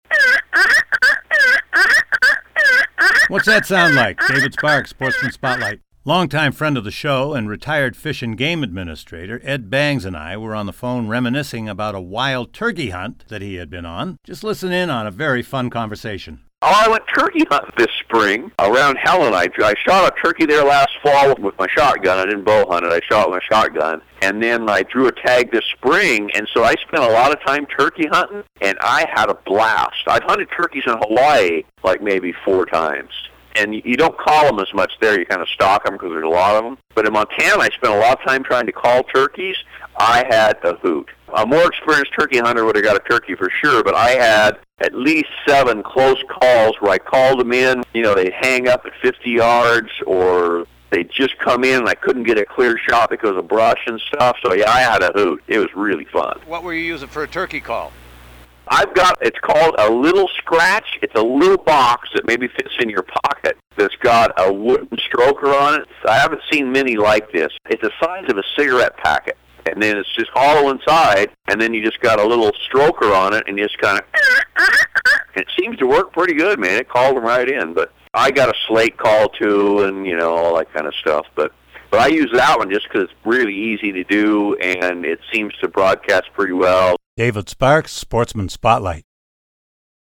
Just a fun conversation.